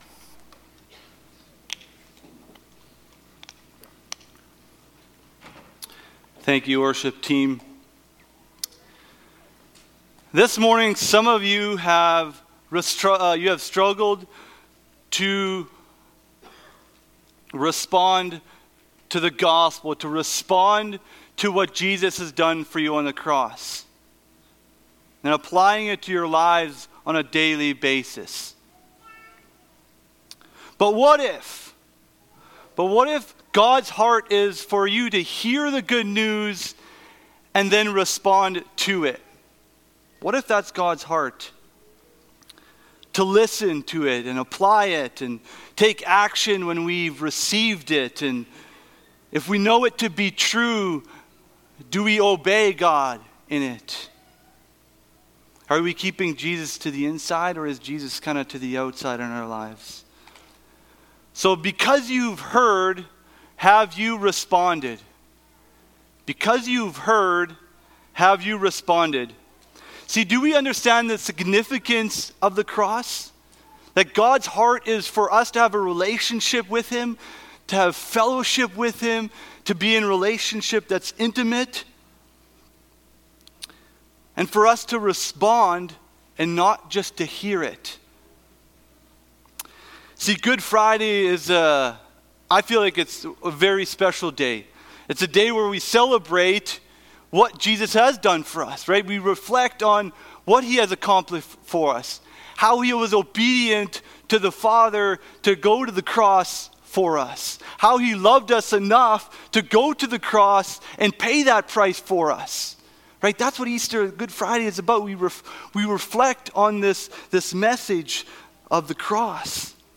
Passage: Roman 1:1-7 Service Type: Good Friday Topics